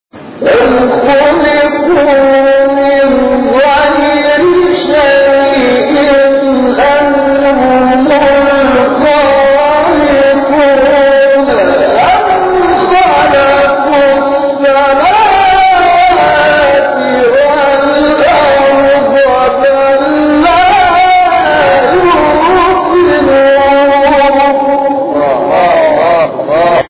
گروه شبکه اجتماعی: نغمات صوتی از تلاوت‌های قاریان به‌نام کشور را می‌شنوید.